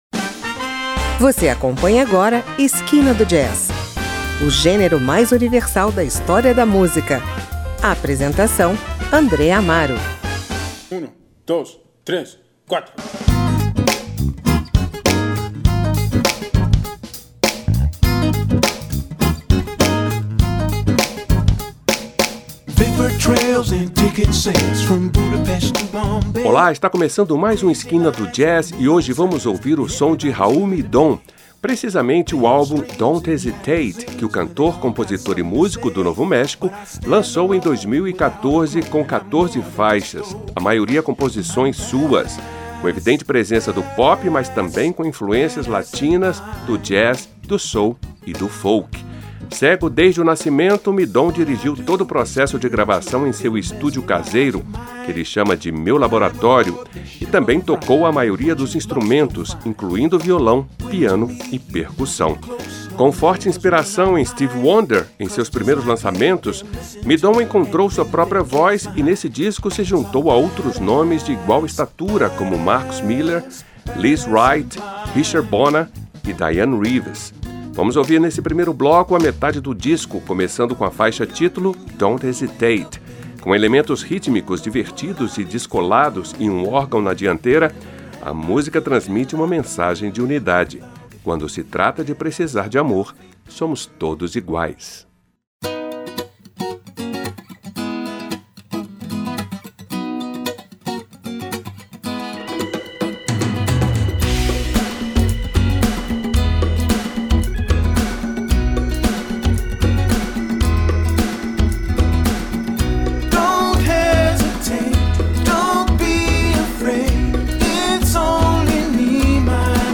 violão, piano e percussão